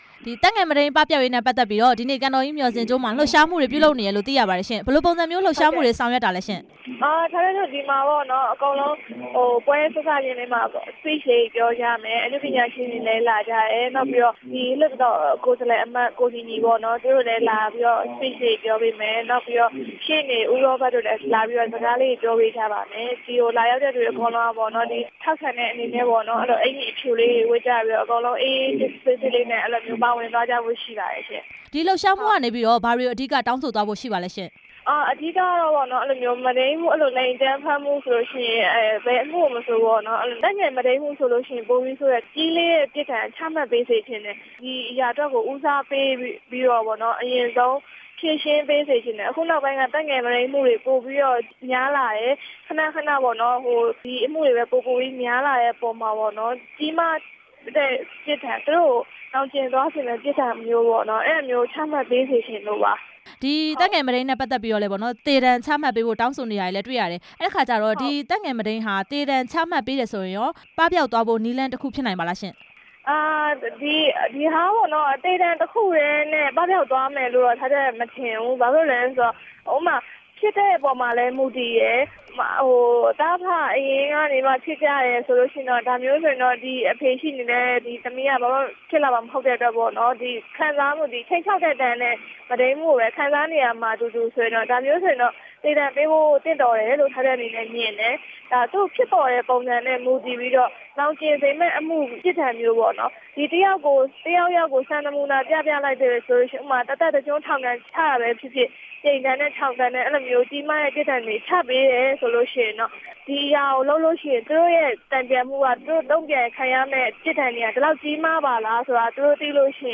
အနုပညာရှင် ထားထက်ထက်နဲ့ မေးမြန်းချက်
ဆက်သွယ်မေးမြန်းထားပါတယ်။